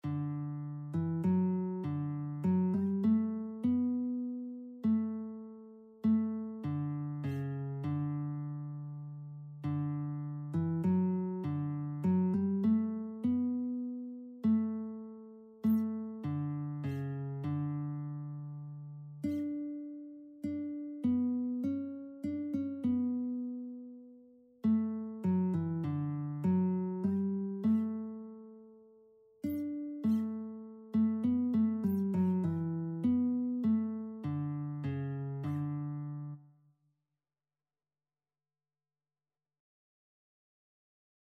Traditional Music of unknown author.
4/4 (View more 4/4 Music)